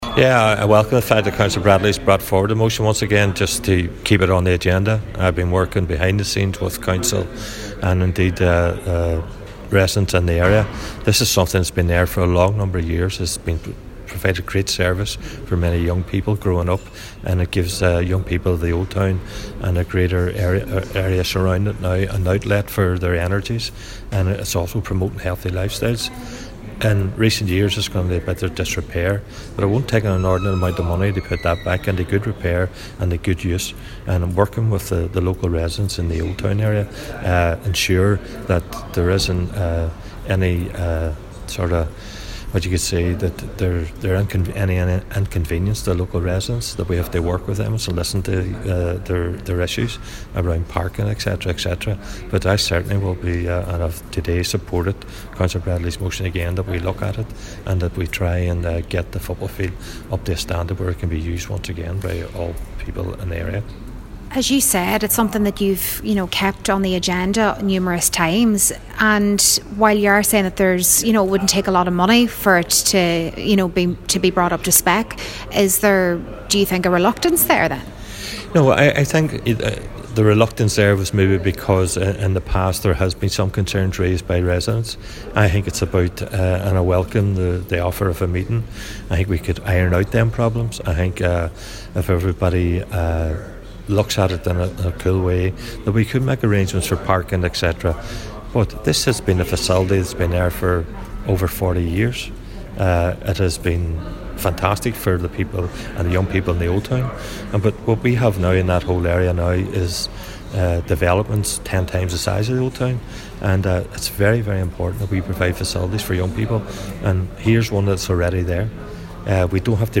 Local Cllr Gerry McMonagle says it wouldn’t take a lot of money to bring the facility back up to spec.